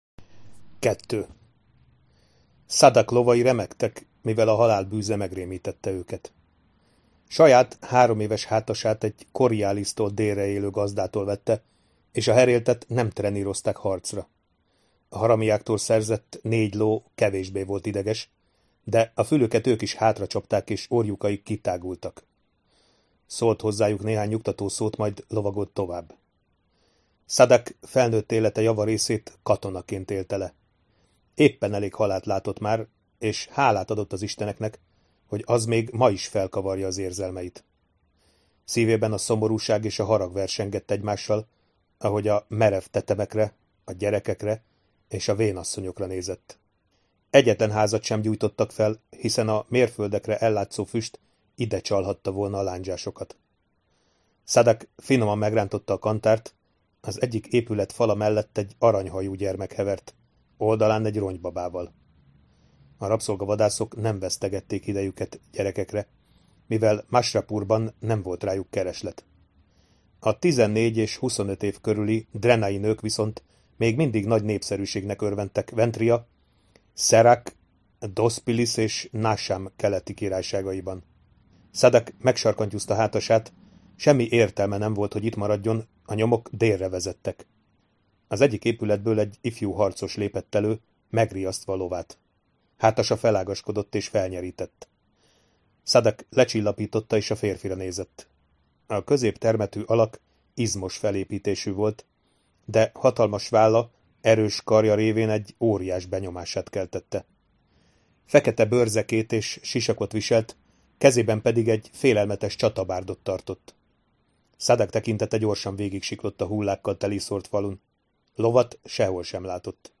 David Gemmell: Legendás Druss első krónikája Hangoskönyv